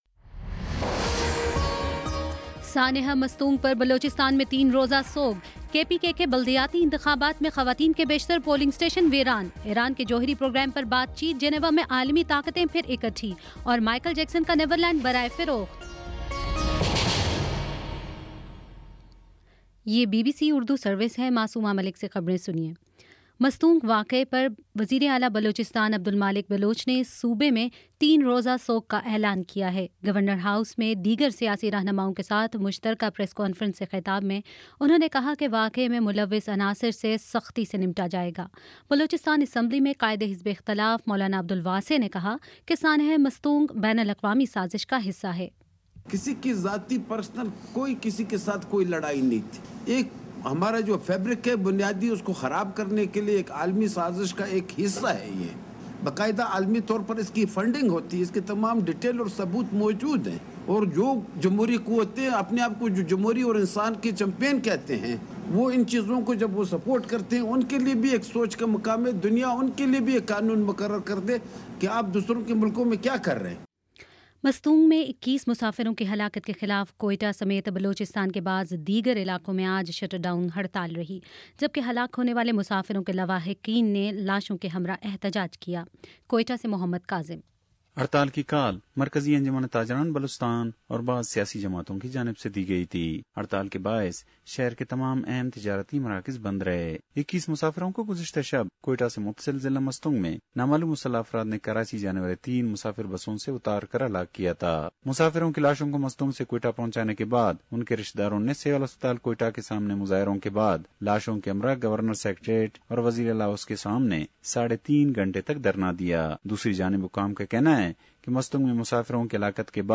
مئی 30: شام پانچ بجے کا نیوز بُلیٹن